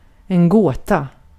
Ääntäminen
IPA : /ˈpʌz.əl/